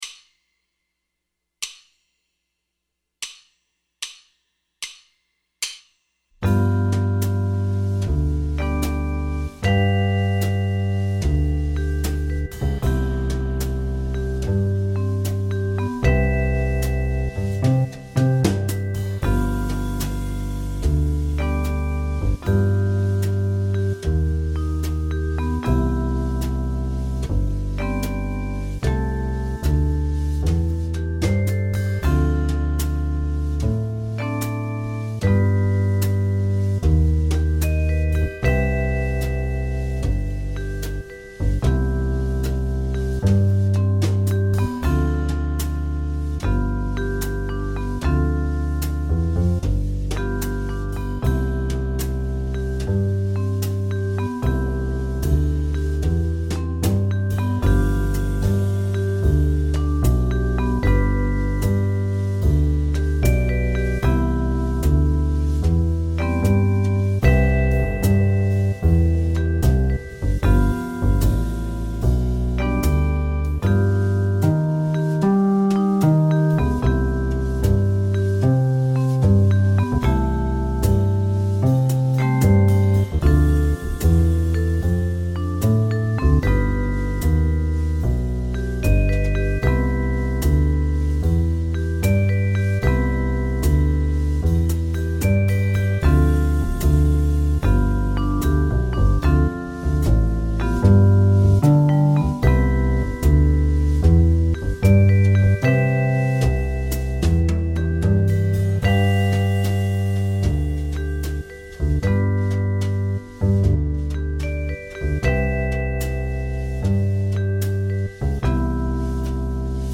Phrases Bebop – ii V7 I Majeur – #06
Transcription en C Majeur